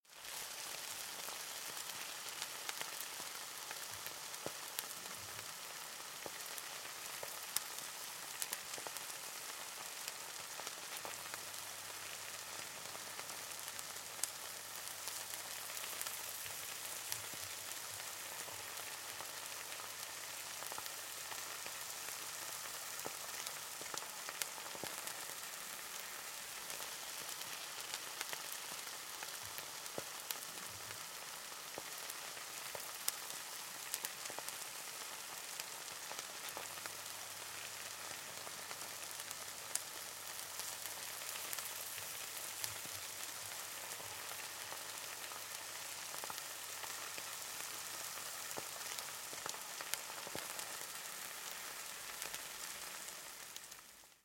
Звуки картошки